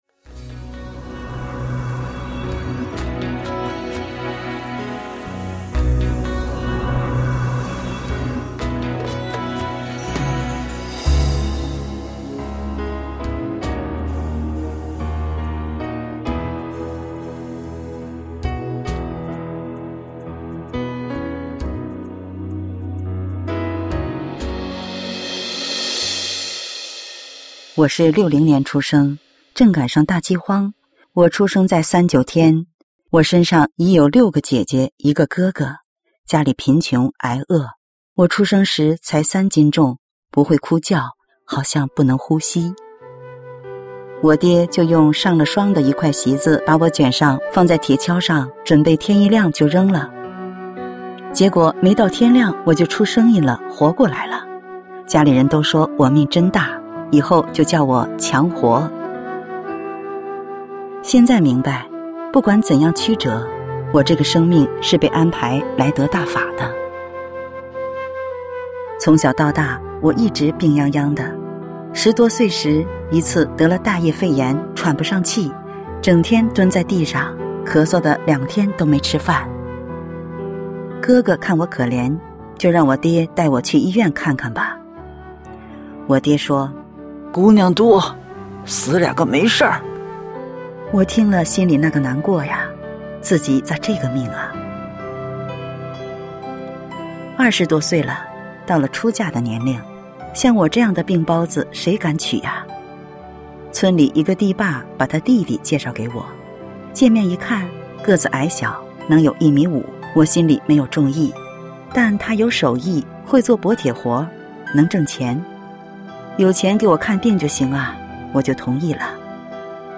朗讀